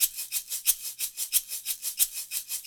Shaker 10 Big Red .wav